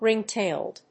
アクセントríng‐tàiled